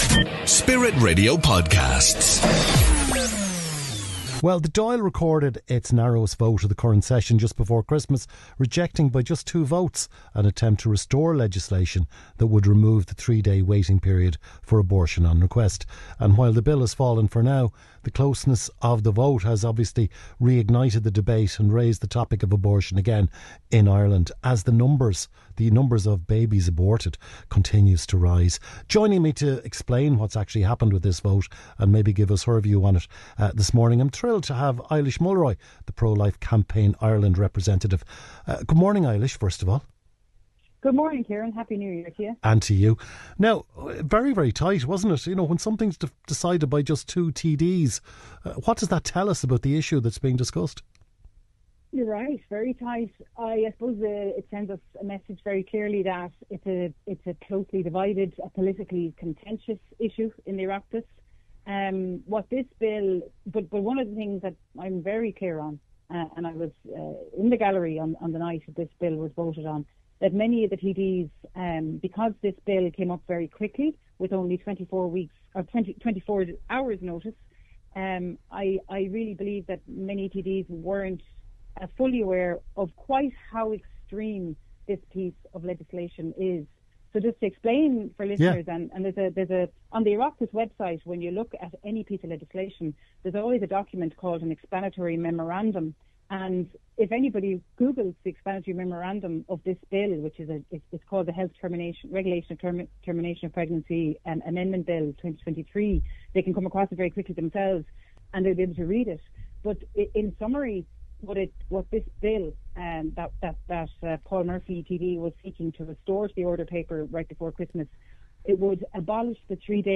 Spirit-Radio-Interview.mp3